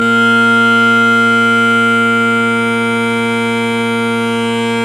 Listen here: Robertsons with Canning drone reeds We put the Wygents that were in his Robertsons in my Centers, listen here: 3/4 Centers with Wygent drone reeds